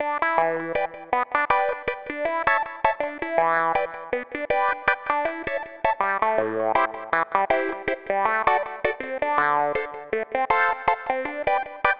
时髦的拍子塔布拉80
标签： 80 bpm Funk Loops Tabla Loops 2.02 MB wav Key : Unknown
声道立体声